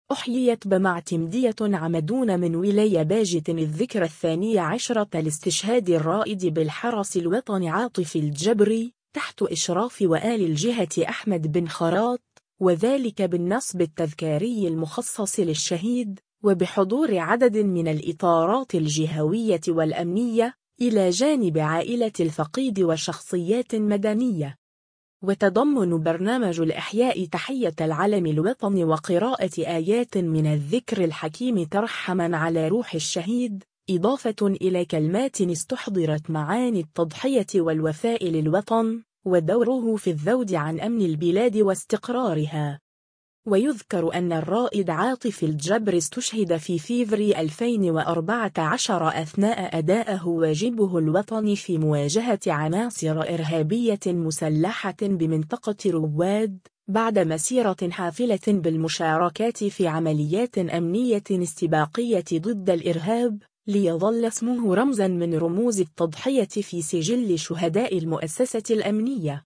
وتضمّن برنامج الإحياء تحية العلم الوطني وقراءة آيات من الذكر الحكيم ترحّمًا على روح الشهيد، إضافة إلى كلمات استحضرت معاني التضحية والوفاء للوطن، ودوره في الذود عن أمن البلاد واستقرارها.